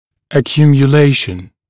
[əˌkjuːmjə’leɪʃn][эˌкйу:мйэ’лэйшн]скопление, накопление, аккумуляция